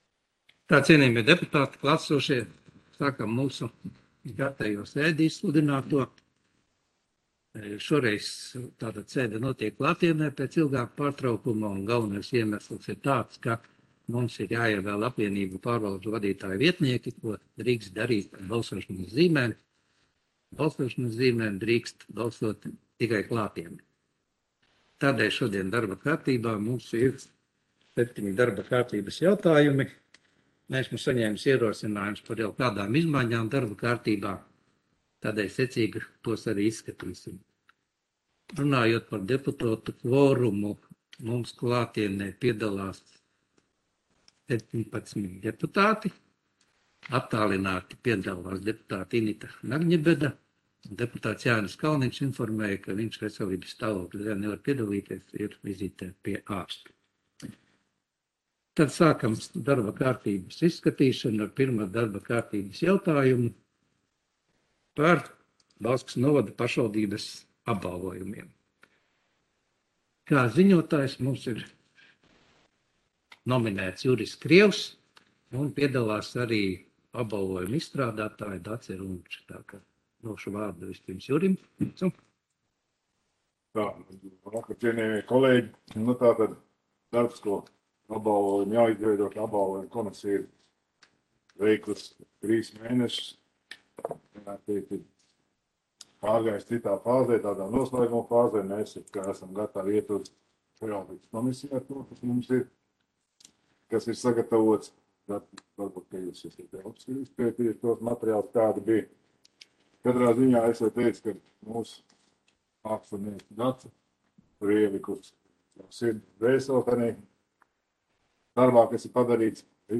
Audioieraksts - 2022.gada 14.februāra domes sēde